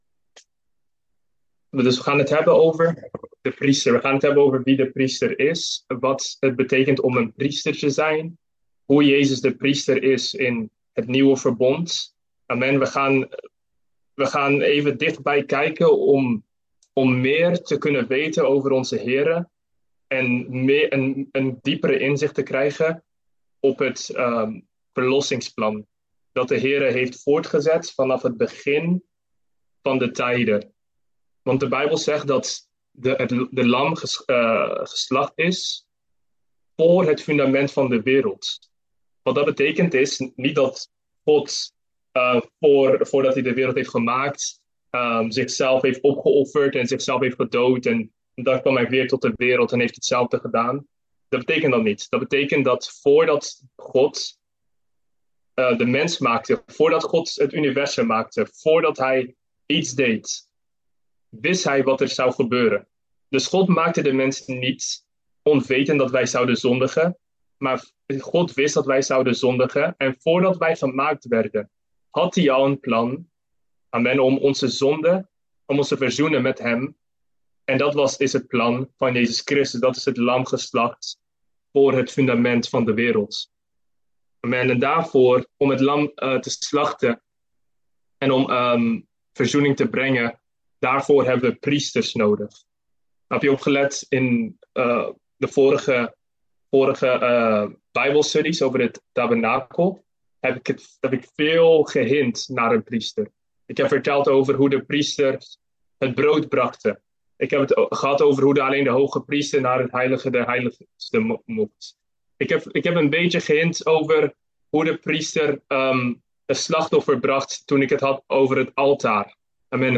Tabernakel Dienstsoort: Bijbelstudie « Zoals het hart is